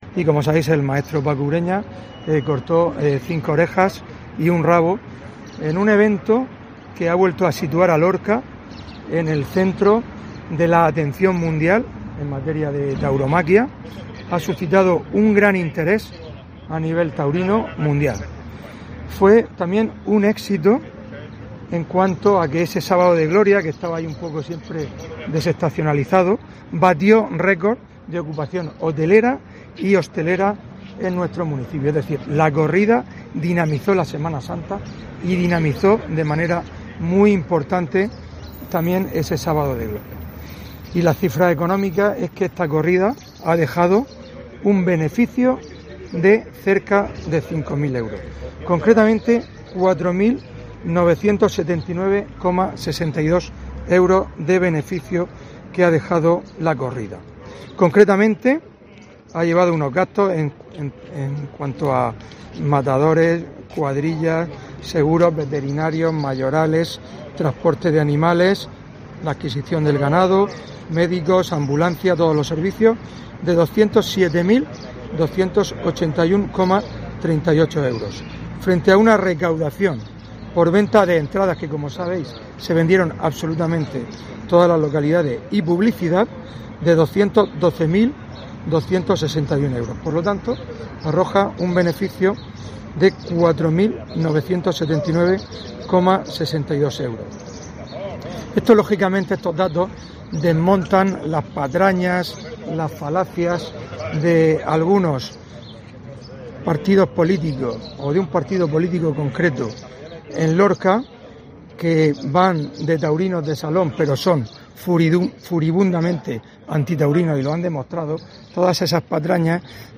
Fulgencio Gil, alcalde de Lorca